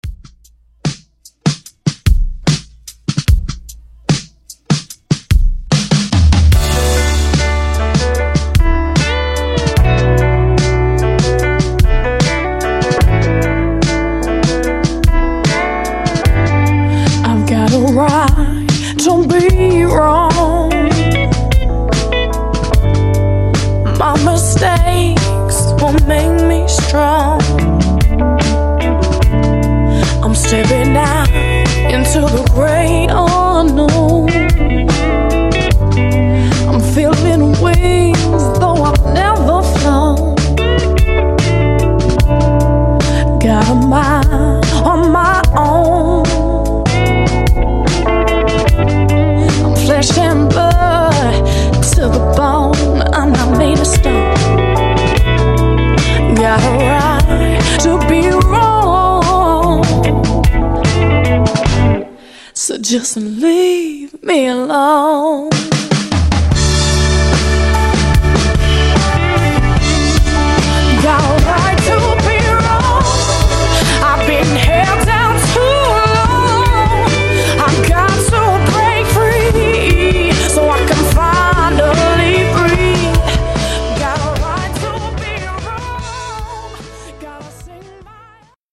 Version: Clean BPM: 110 Time